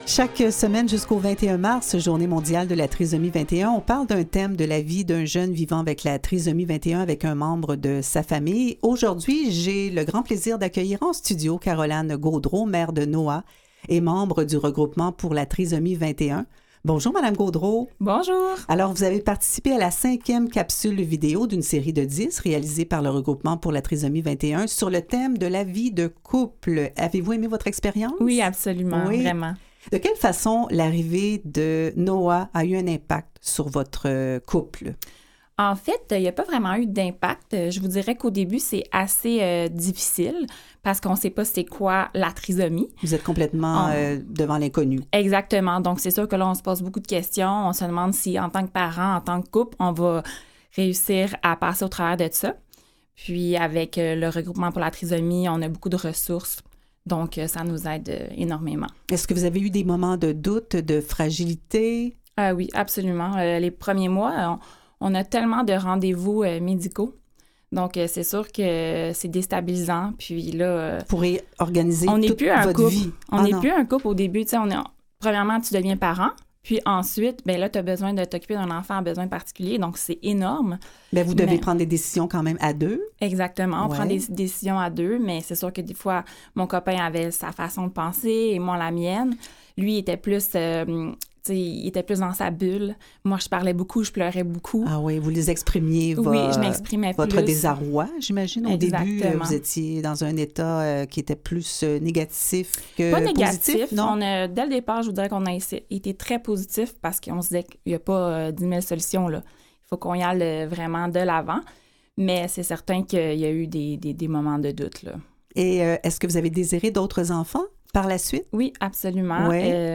LES ENTREVUES DU JOUR